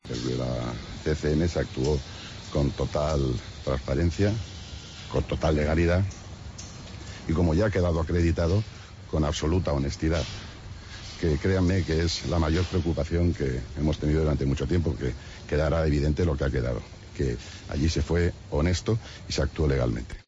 Hernández Molto habla a la salida del juicio defendiendo su inocencia